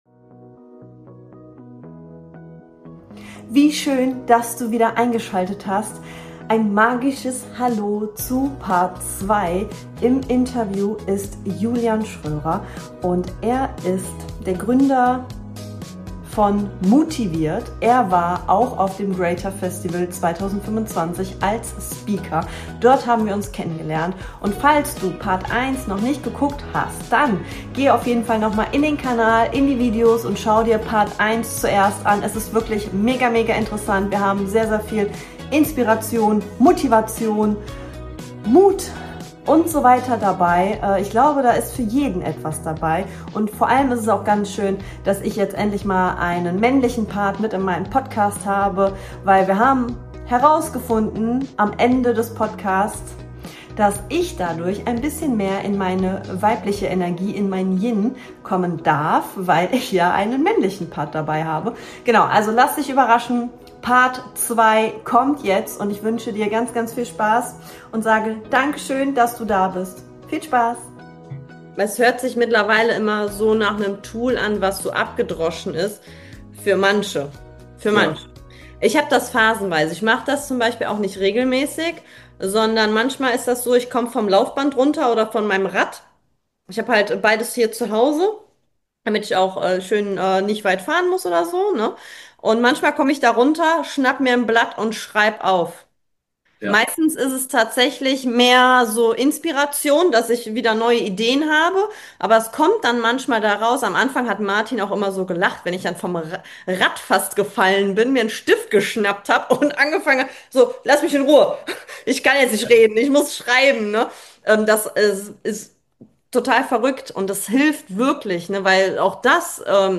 Zwei Coaches im ehrlichen Austausch über mentale Stärke und Lebenswege
Interview